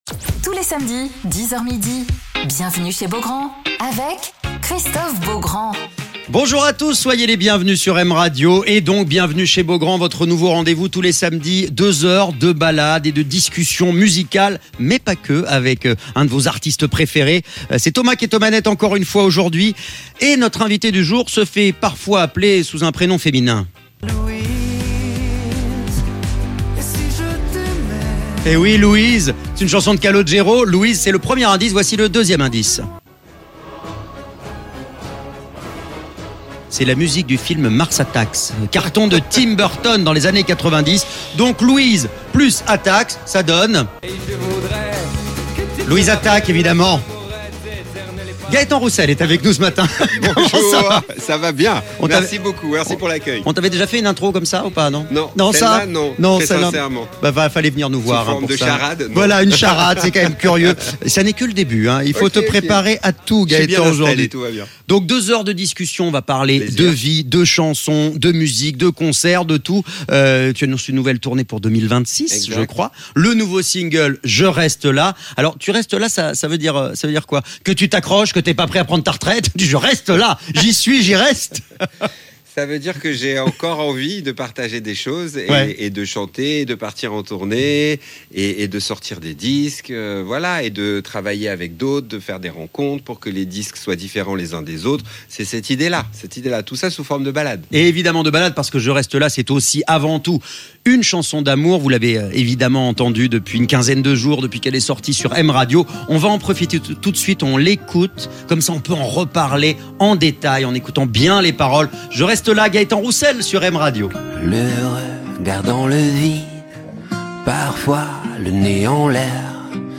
Alors qu'il est de retour avec son nouveau titre "Je reste là", Gaëtan Roussel est l'invité de Christophe Beaugrand sur M Radio